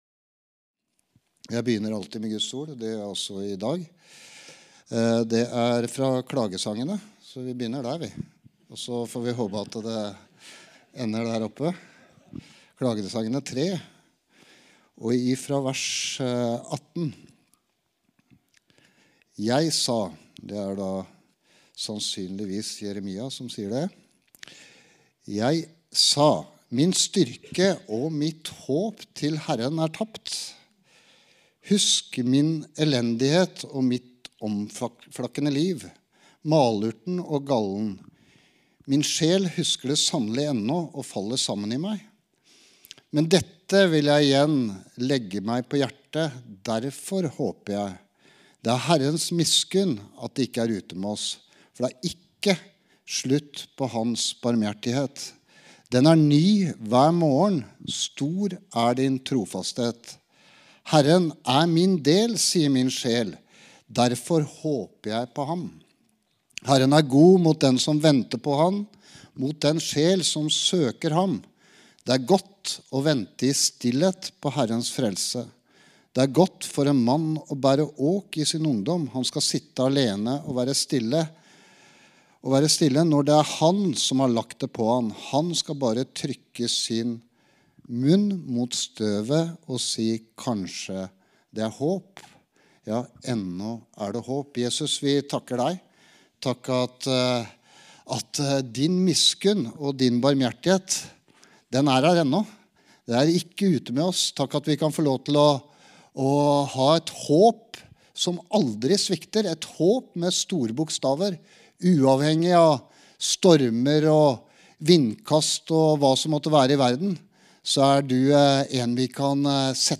Gudstjeneste